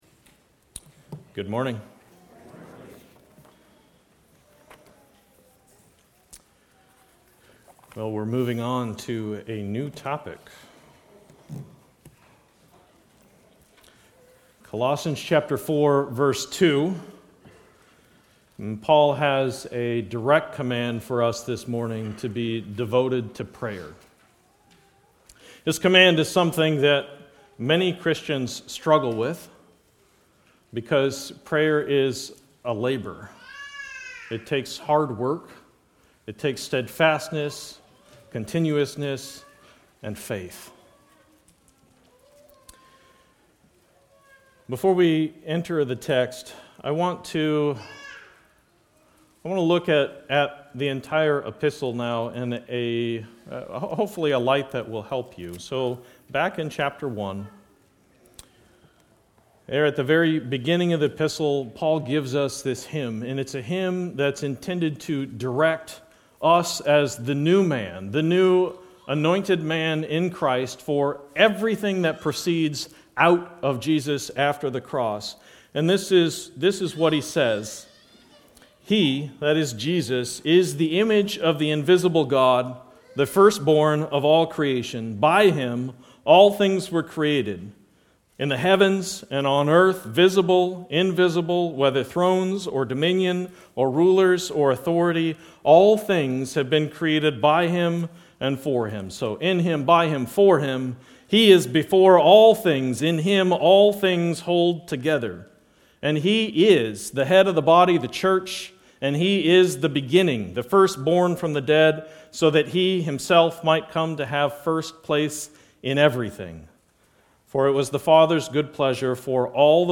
Sermons on the Family